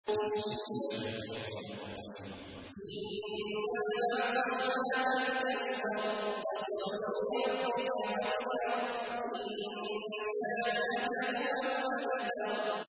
تحميل : 108. سورة الكوثر / القارئ عبد الله عواد الجهني / القرآن الكريم / موقع يا حسين